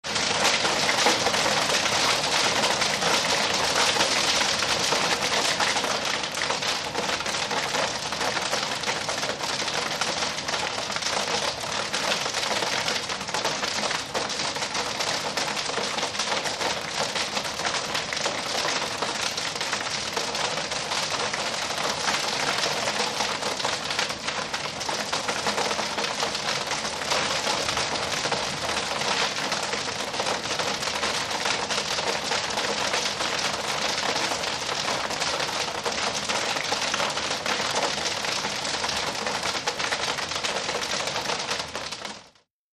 Hail, mothball size clattering on roof